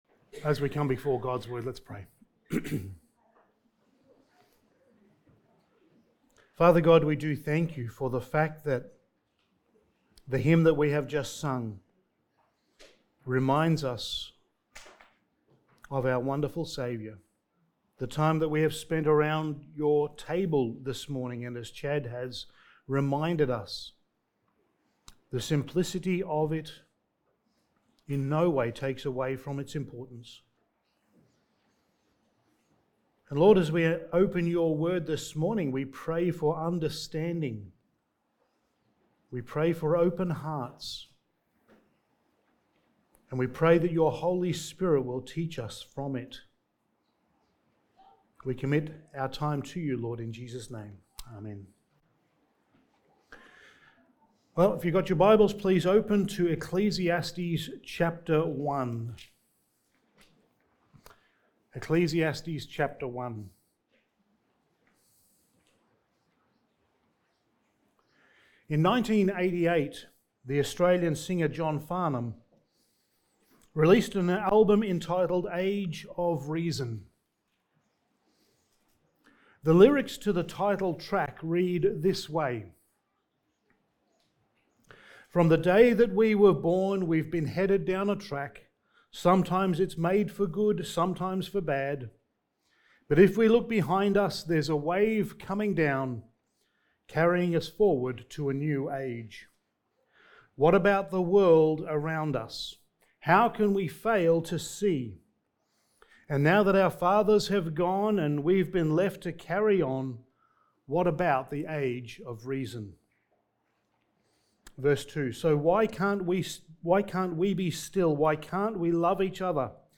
Studies in the Book of Ecclesiastes Sermon 3: The Vanity of Wisdom
Service Type: Sunday Morning